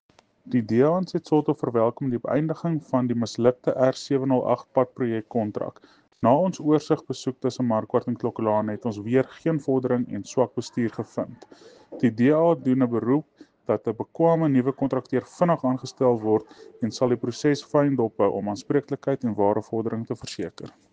Afrikaans soundbites by Cllr Jose Coetzee and Sesotho soundbite by Cllr Kabelo Moreeng.